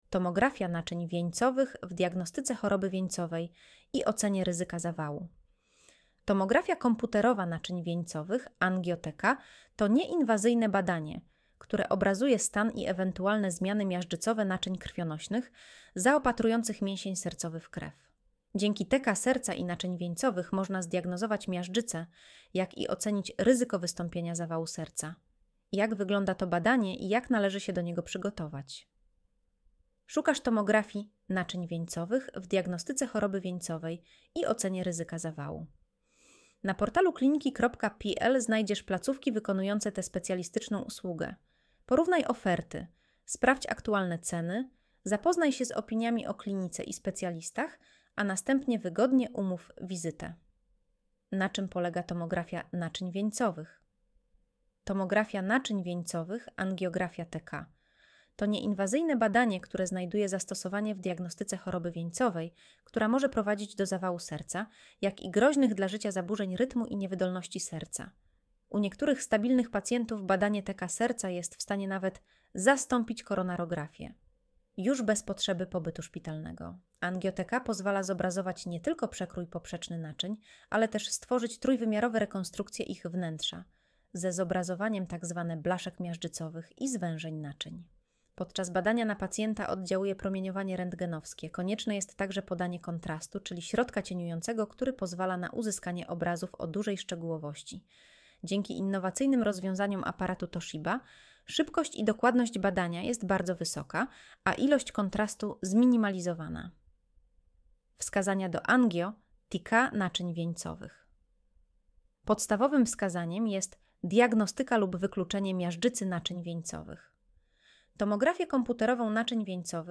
Streść artykuł Słuchaj artykułu Audio wygenerowane przez AI, może zawierać błędy 00:00